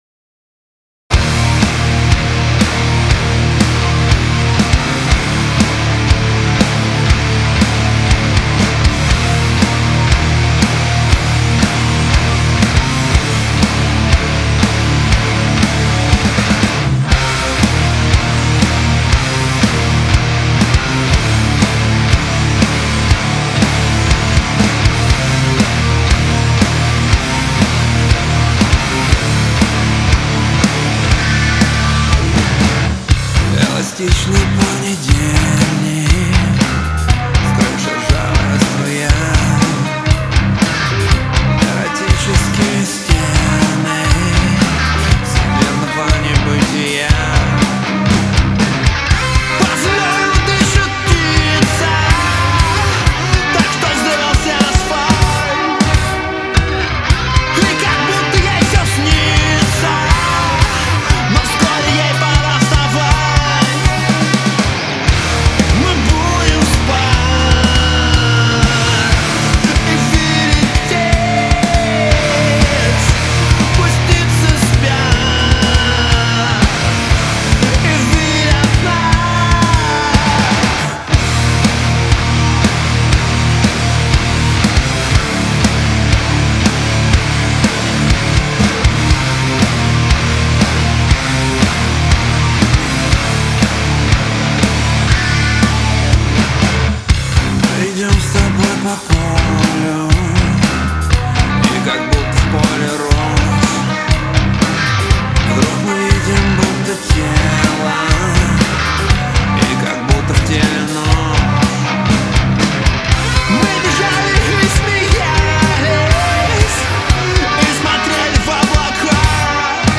Студийный Живак (Rock)
Писали в небольшой комнате, инструменты все сразу, вокал дописывали.